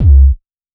• 2000s Wet Bass Drum Single Hit C Key 361.wav
Royality free kick drum single hit tuned to the C note. Loudest frequency: 73Hz